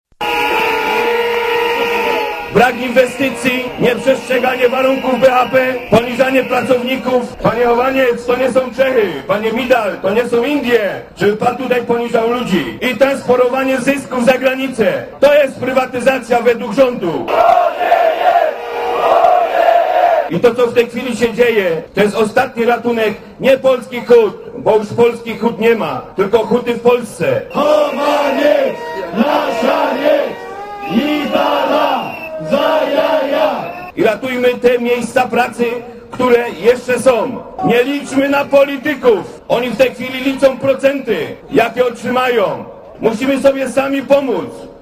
Huk petard, świece dymne, syreny i trumna symbolizująca śmierć polskiego hutnictwa.
Komentarz audio